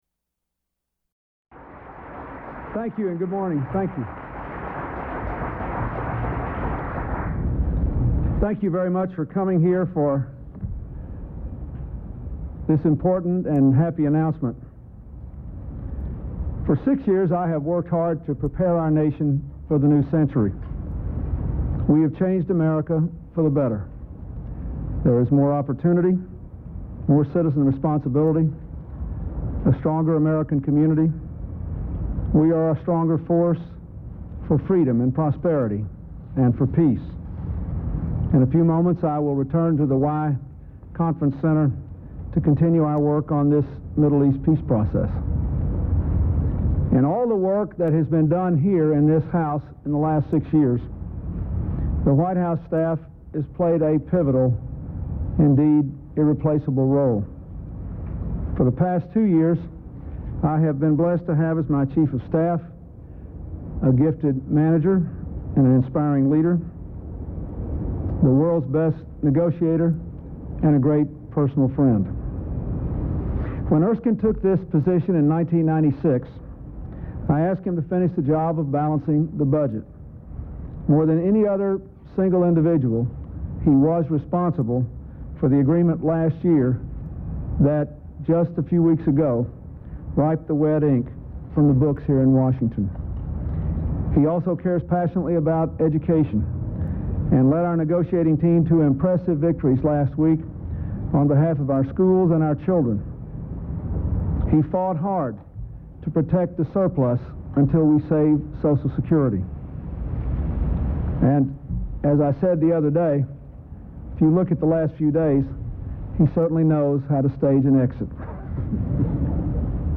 President Bill Clinton announces the retirement of White House Chief of Staff Erskine Bowles and the appointment of John Podesta. Held at the White House Rose Garden.